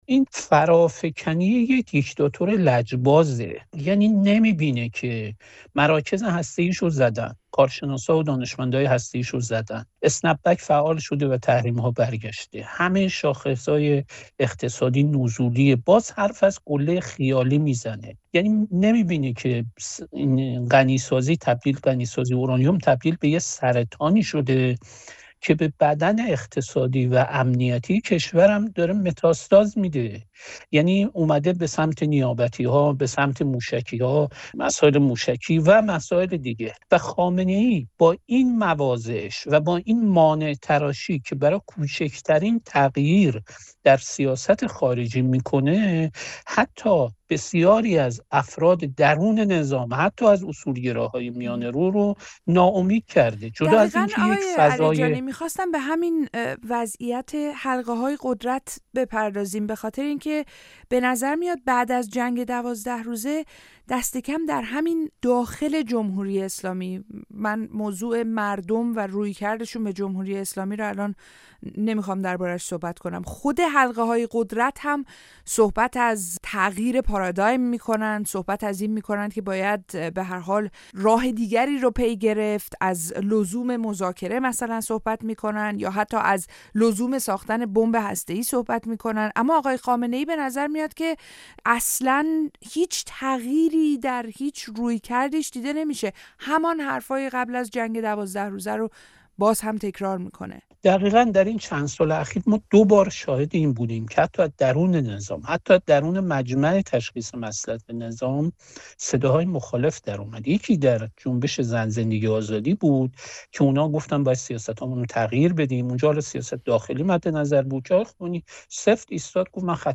«لجبازی خامنه‌ای» در گفت‌وگو